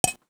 DelayTink4.wav